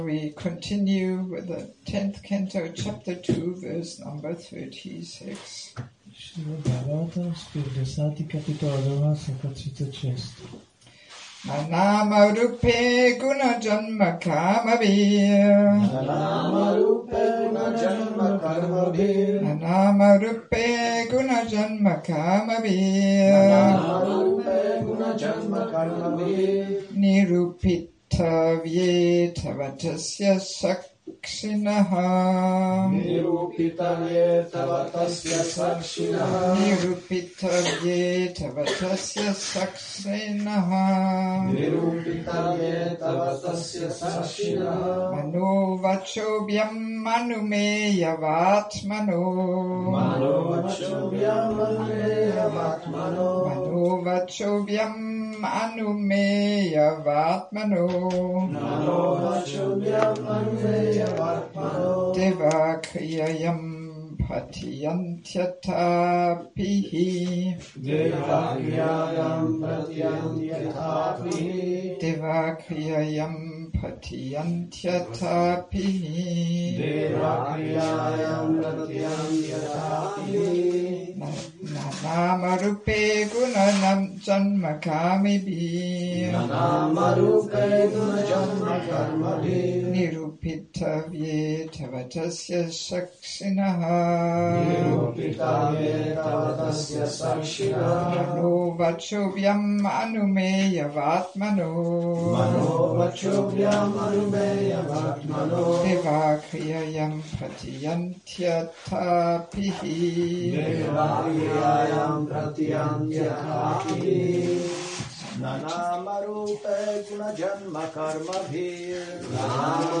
Přednáška SB-10.2.36 – Šrí Šrí Nitái Navadvípačandra mandir